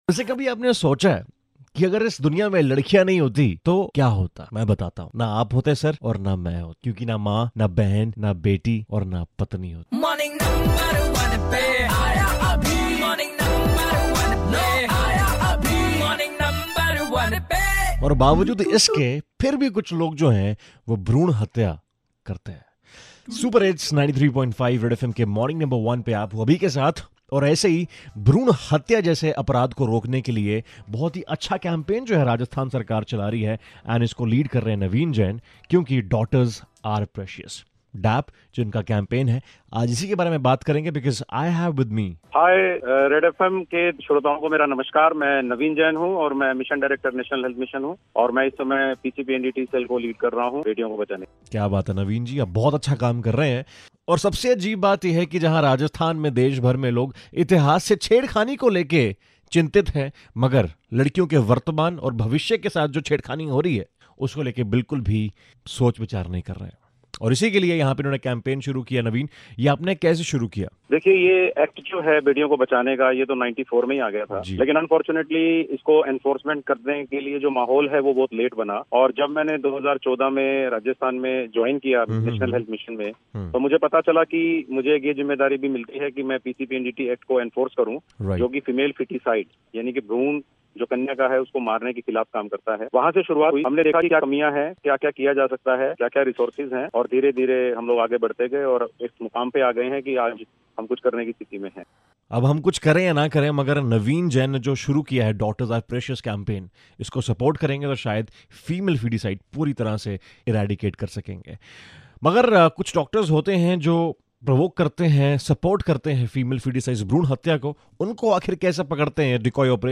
in conversation with IAS Navin Jain, Mission Director, National Health Mission